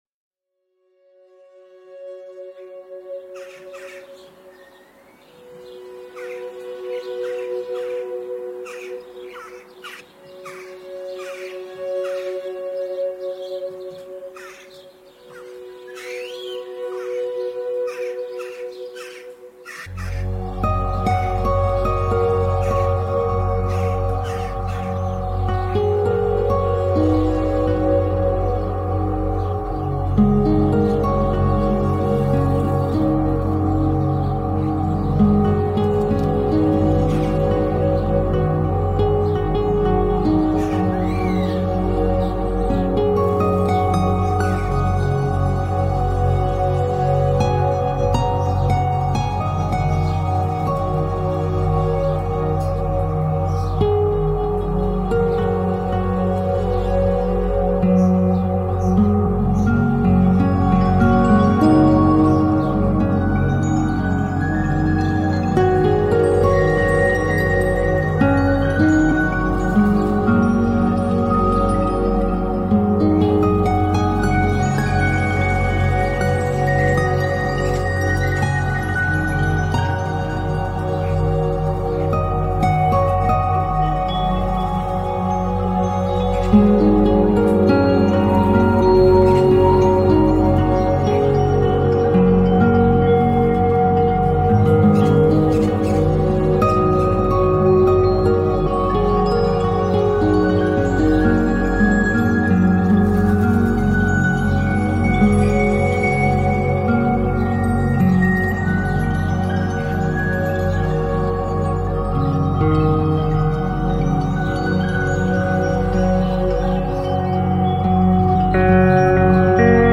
Многослойная акустическая экосистема, объединяющая:
• Разнотембровые акустические и электронные инструменты
• Полимодальные гармонические структуры
• Естественные звуки окружающей среды